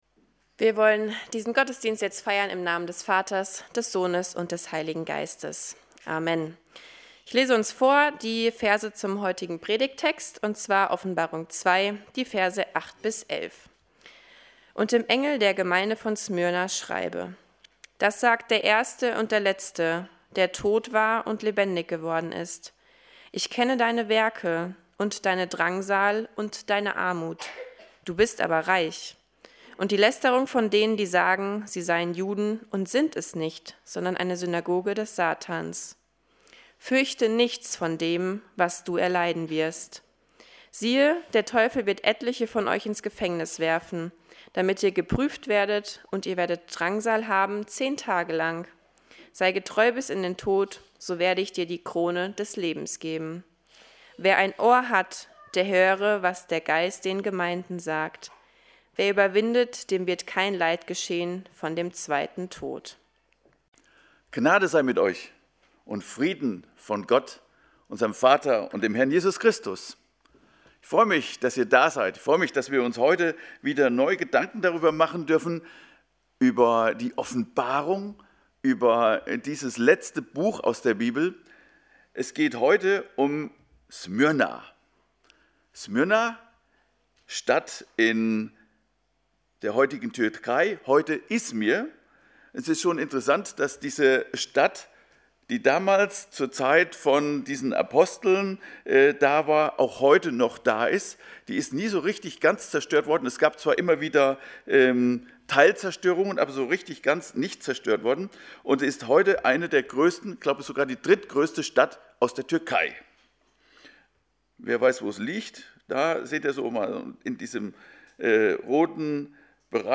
Predigten – Seite 20 – Evangelische Gemeinschaft Kredenbach